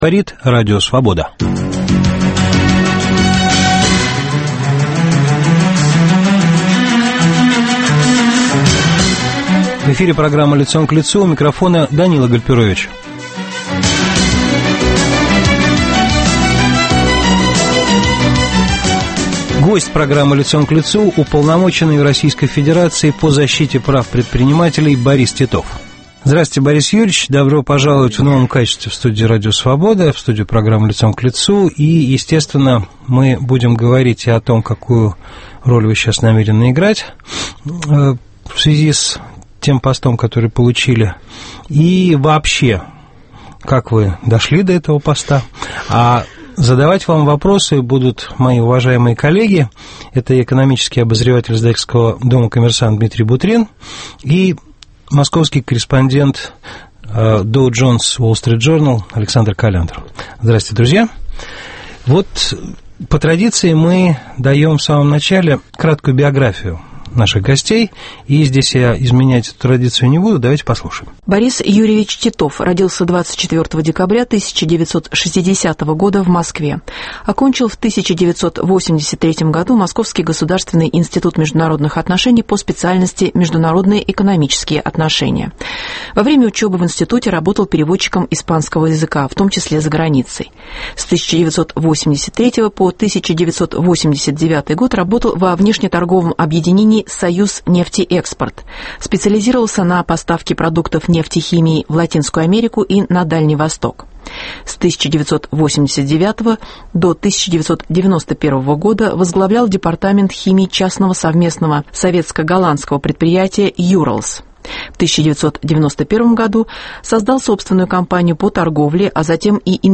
В программе - уполномоченный по защите прав предпринимателей в России Борис Титов.